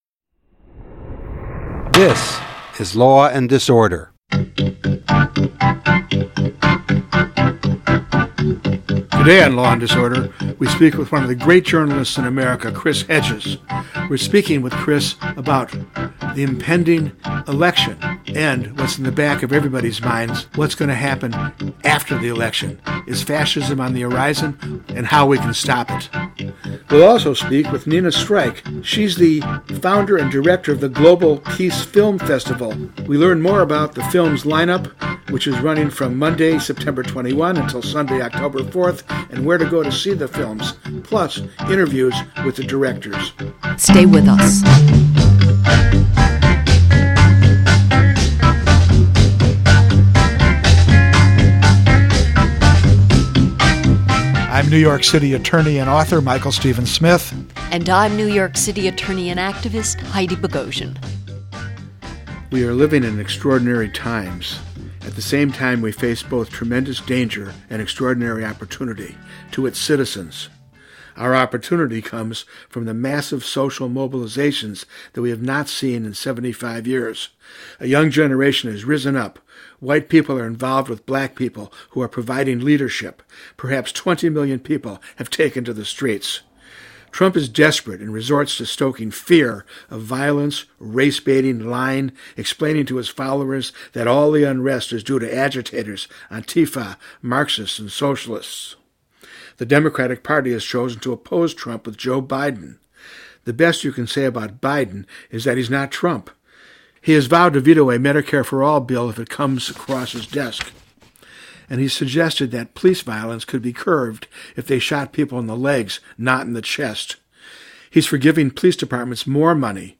Guest – Professor Richard Wolff , author of The Sickness is the System: When Capitalism Fails To Save Us From Pandemics or Itself.